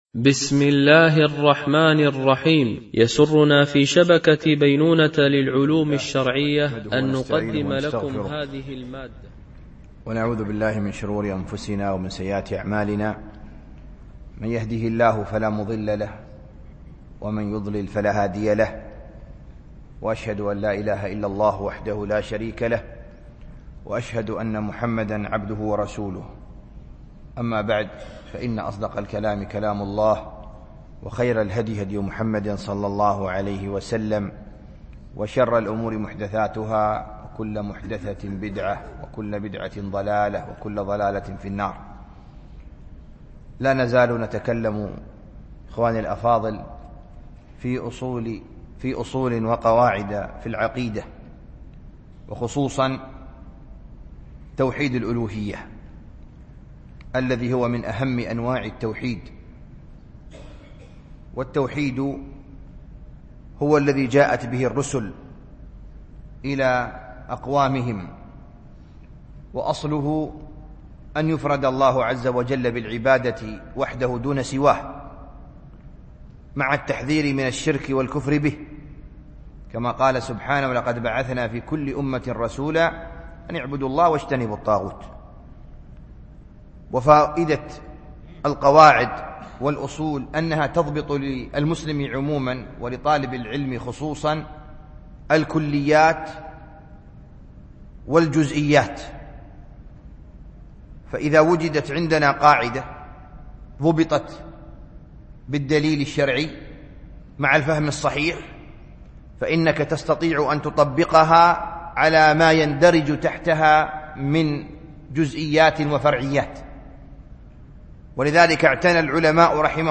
الدرس التاسع عشر